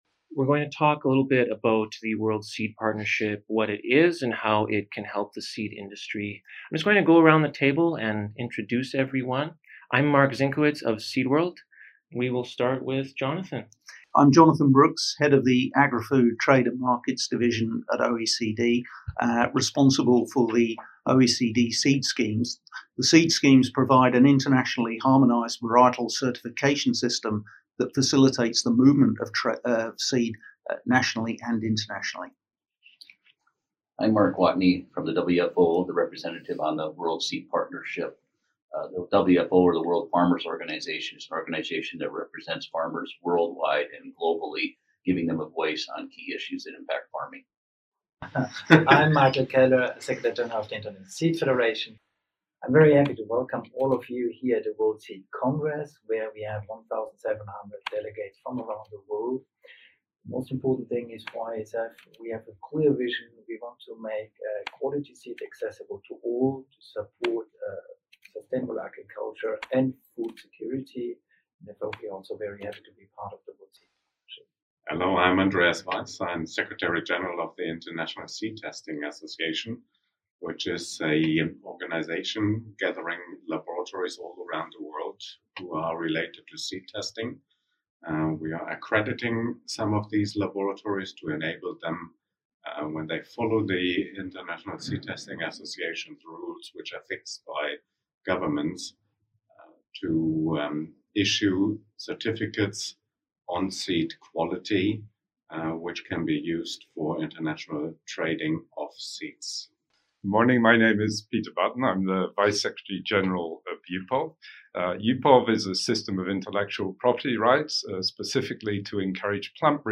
Roundtable Discussion — Catching Up with the World Seed Partnership
Interview conducted at the International Seed Federation’s 2019 World Seed Congress in Nice, France.
Roundtable-Discussion-—-Catching-Up-with-the-World-Seed-Partnership.mp3